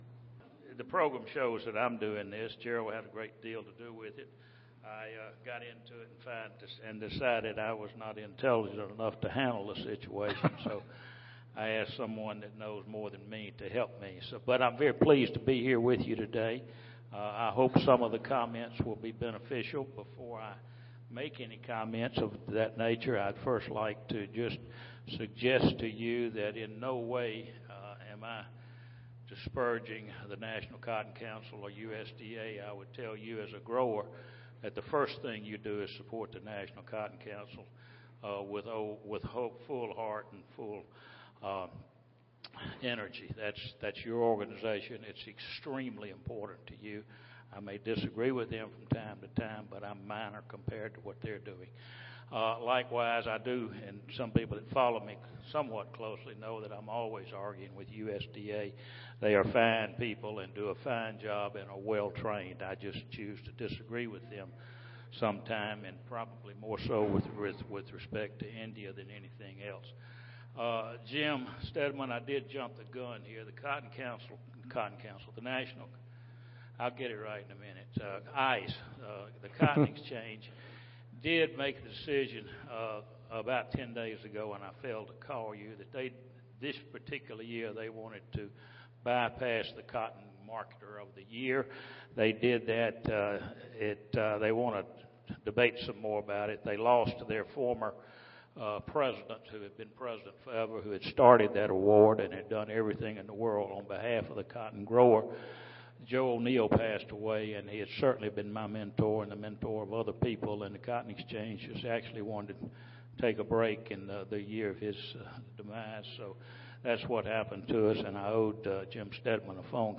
Mardi Gras Ballroom Salon D (New Orleans Marriott)
Recorded Presentation U.S. Price Outlook